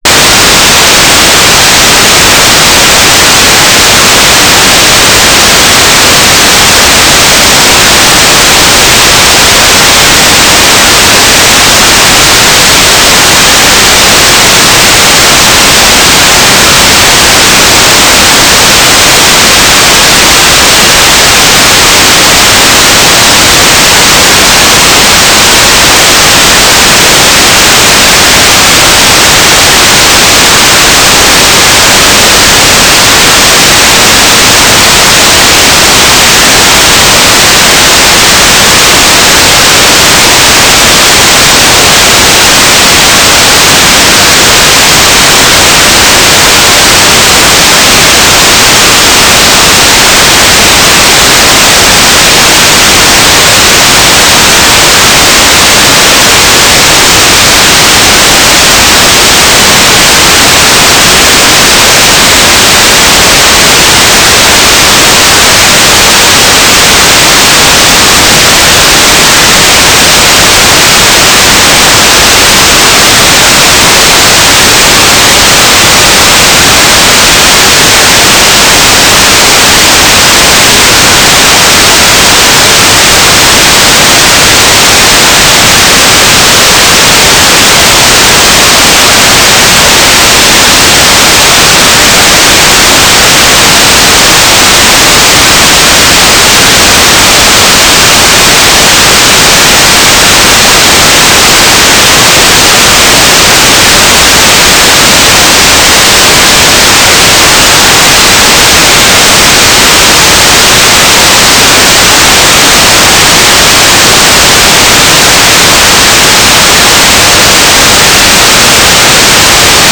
400.500 MHz is unfortunate because there are very strong & near constant 500k LoRa packets on 400.450. So, in any given observation, don’t be surprised to see a lot of very wideband signals.